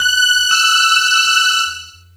Synth Lick 50-05.wav